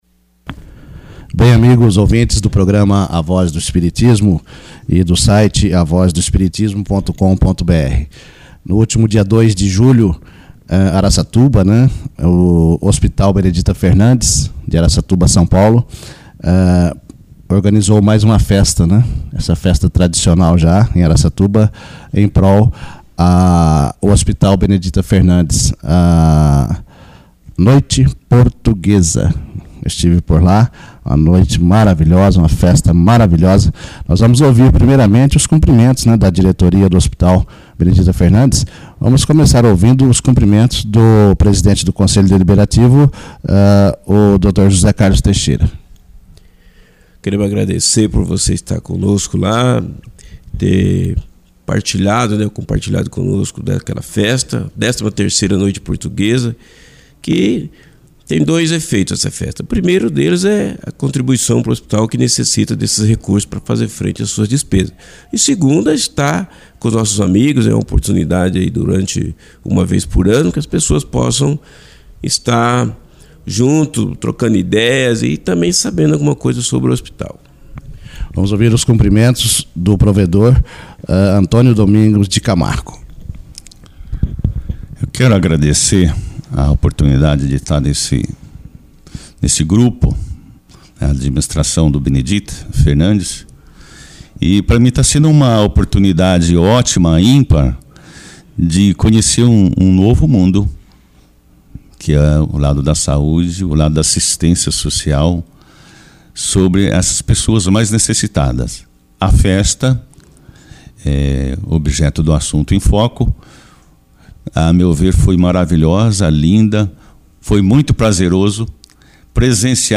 Registro da festa beneficente ao hospital Psiquiatrico espírita de Araçatuba SP Benedita Fernandes
entrevista festa portuguesa 09-07.mp3